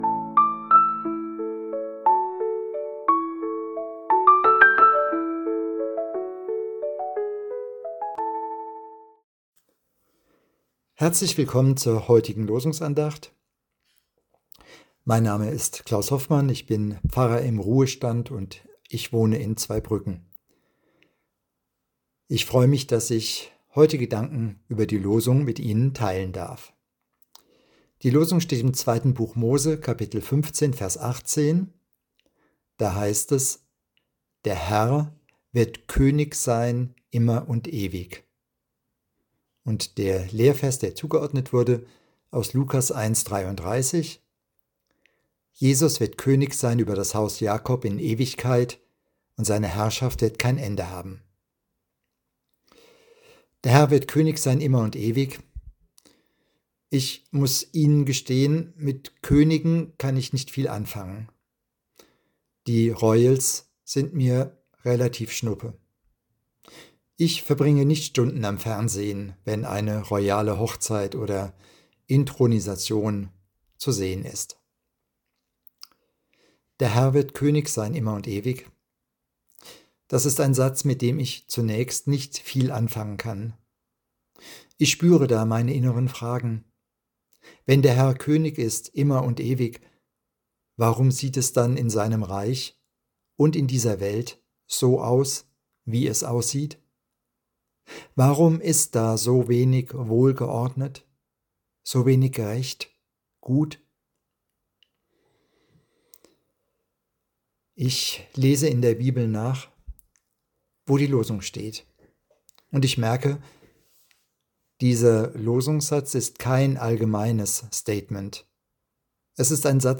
Losungsandacht für Freitag, 23.01.2026 – Prot.